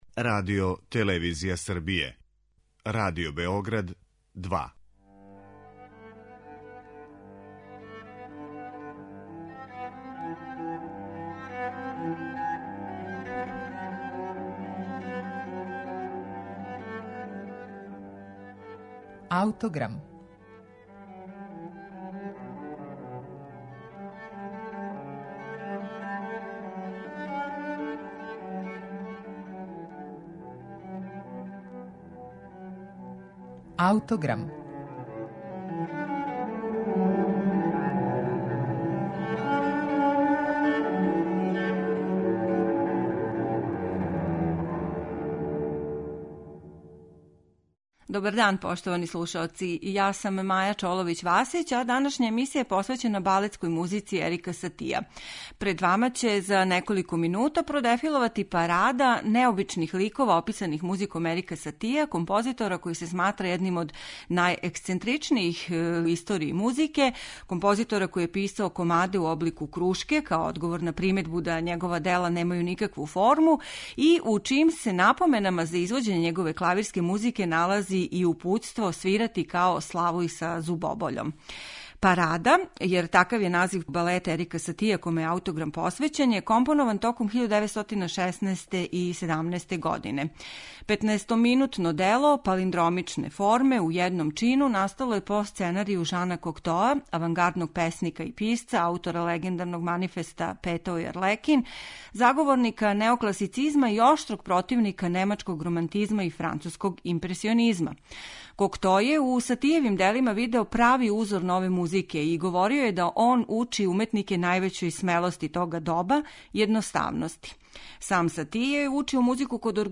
Слушаћете је у извођењу оркестра из Минеаполиса којим диригује Антал Дорати.